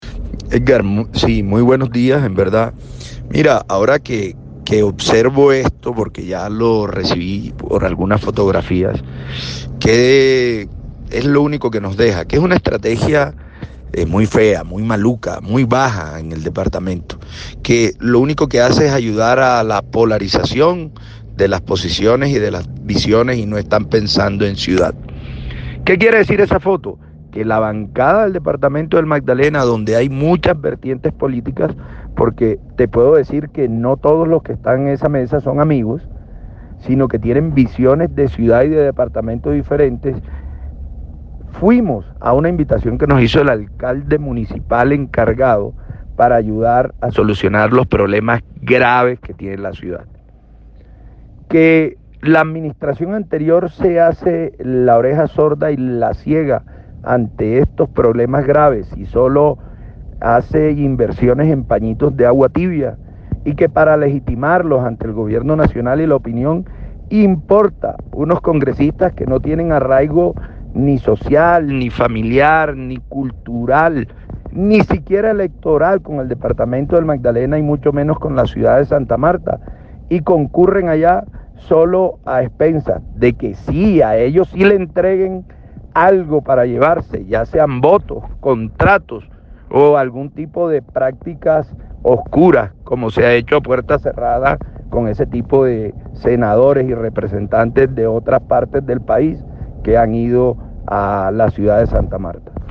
En declaraciones a Fuego Estéreo, el senador Fabián Castillo calificó la estrategia de Fuerza Ciudadana como “muy baja” y aclaró que la reunión fue para contribuir a solucionar los problemas graves de la ciudad, de los que “la administración actual se ha hecho la sorda y la ciega”.
Declaraciones del senador Fabián Castillo en reacción por la valla de Fuerza Ciudadana